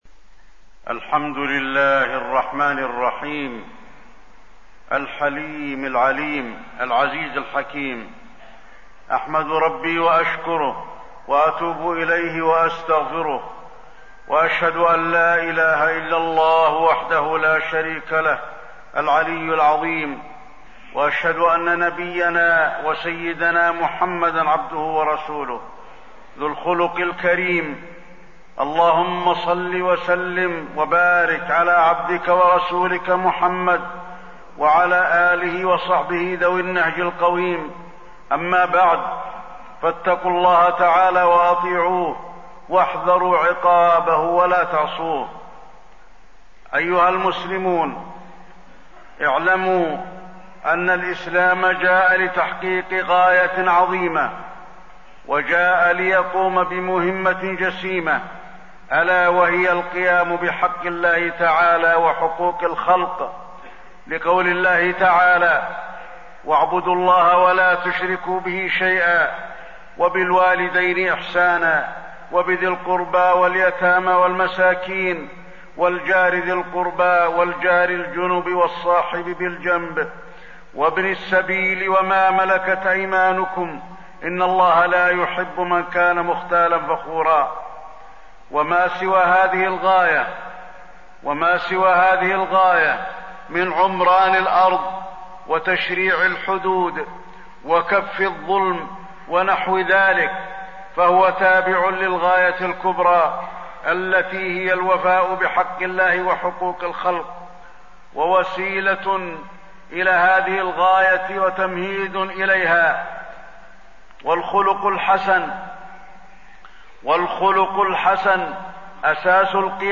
تاريخ النشر ٢٣ جمادى الأولى ١٤٣١ هـ المكان: المسجد النبوي الشيخ: فضيلة الشيخ د. علي بن عبدالرحمن الحذيفي فضيلة الشيخ د. علي بن عبدالرحمن الحذيفي حسن الخلق